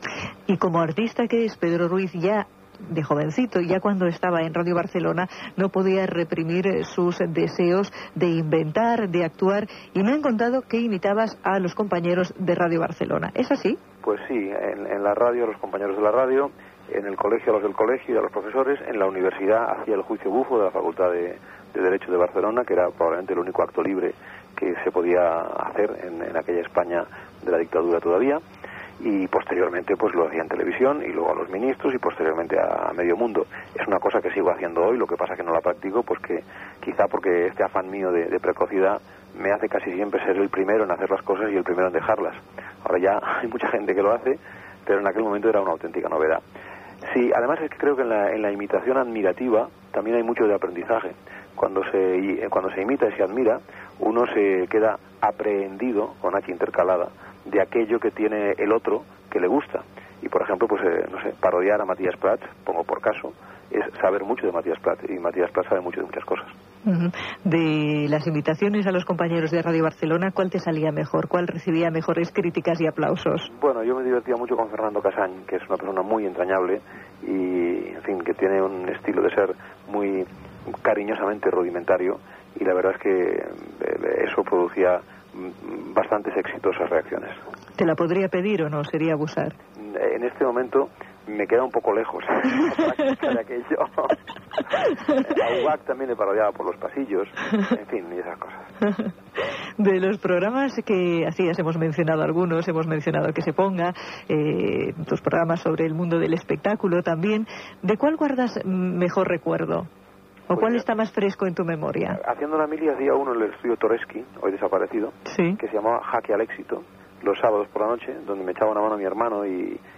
Entrevista telefònica a Pedro Ruiz sobre les seves imitacions i els programes que va presntar "Que se ponga" i "Jaque al éxito"
FM